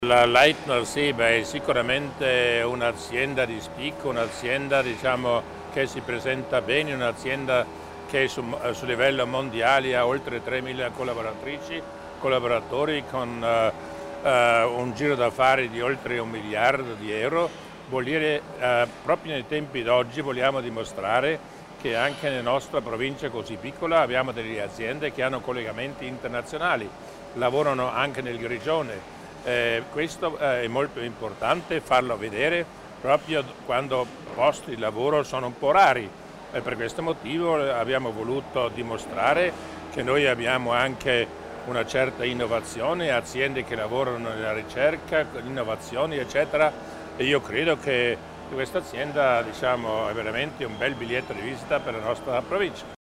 Il Presidente Durnwalder elenca i vantaggi della collaborazione con il Cantone dei Grigioni